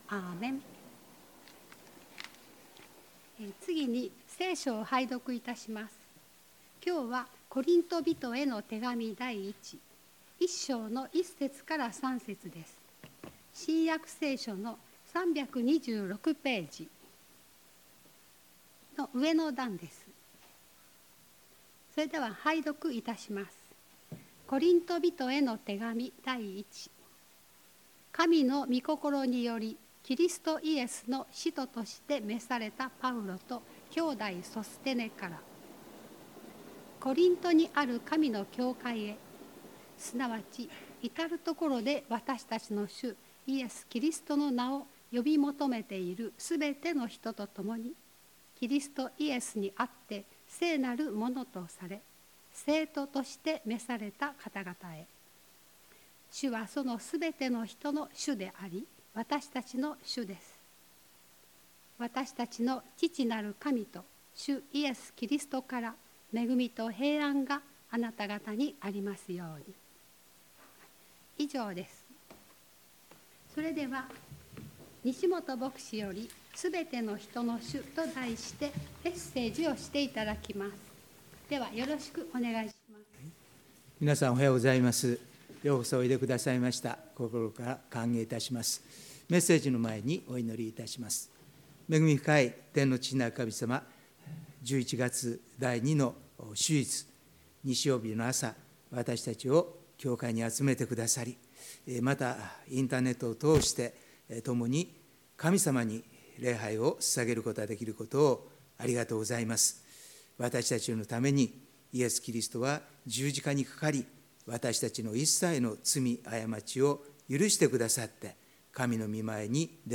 礼拝メッセージ「すべての人の主」│日本イエス・キリスト教団 柏 原 教 会